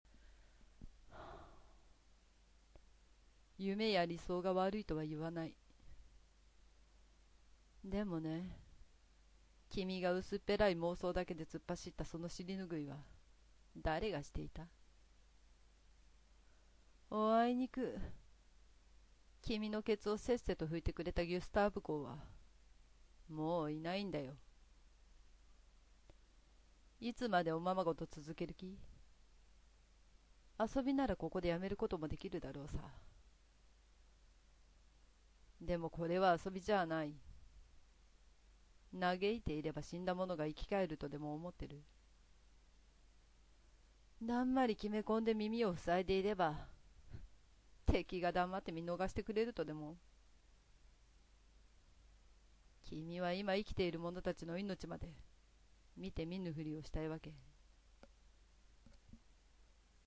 ミストボイス…の、つもりなんだ。